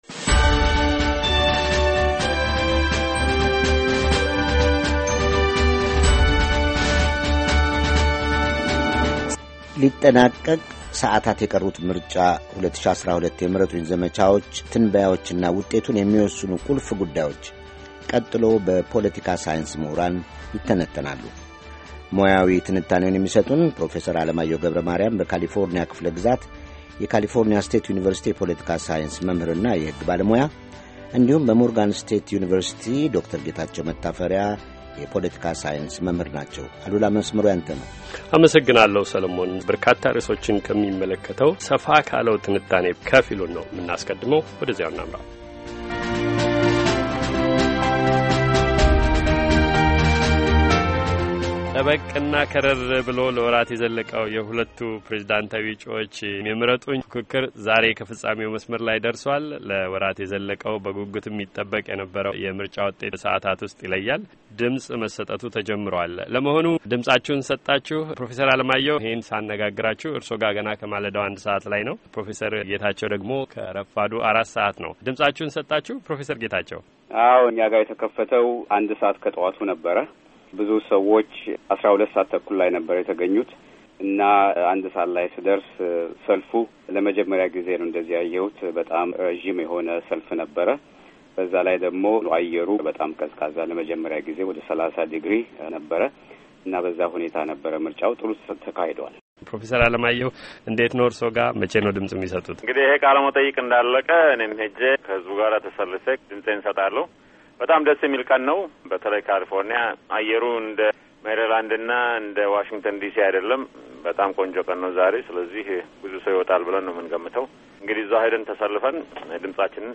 የምርጫ ውጤት ትንበያዎች፤ የምርጫውን ውጤት ይወስናሉ፥ የተባሉ ቁልፍ-ቁልፍ ጉዳዮችና ታሪካዊ በተሰኘ ሂደት በቢሊዮኖች የተሰላ ዶላር የፈሰሰባቸው የምረጡኝ ዘመቻዎች በፖለቲካ ሳይንስ ምሁራን ይዳሰሳሉ።